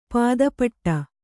♪ pāda paṭṭa